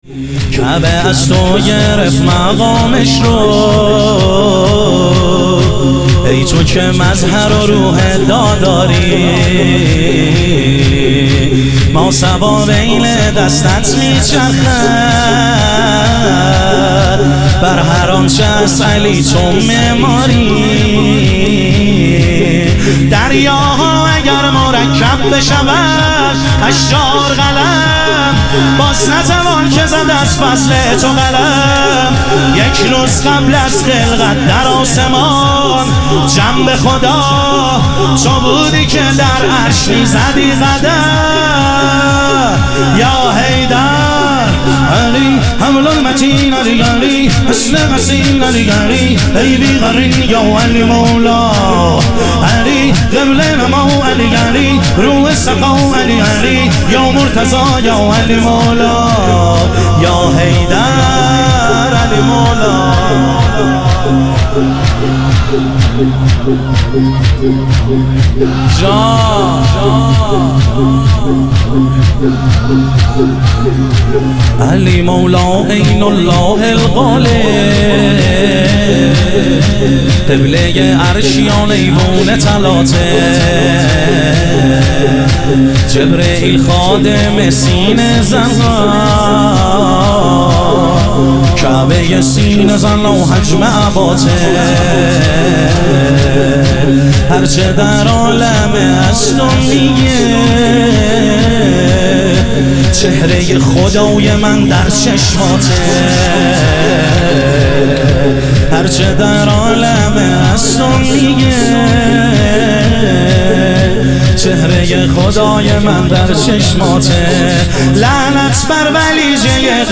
شهادت حضرت سلطانعلی علیه السلام - شور